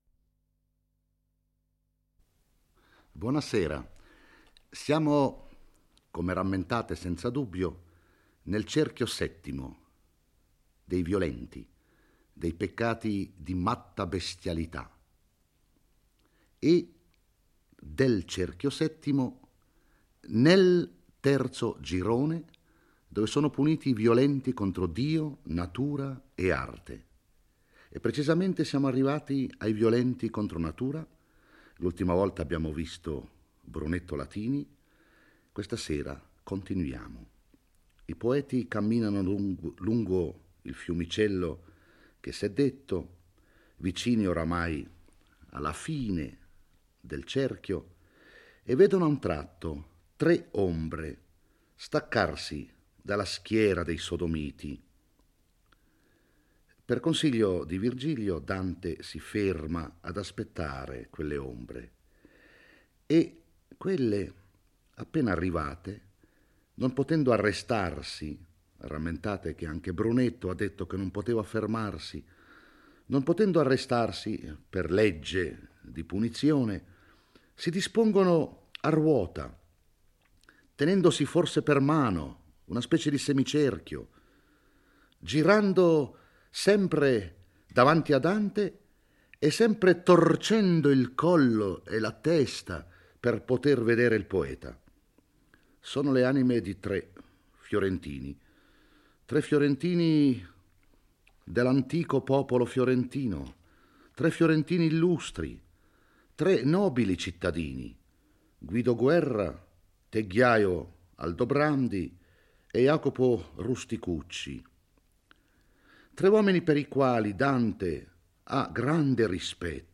legge e commenta il XVI canto dell'Inferno.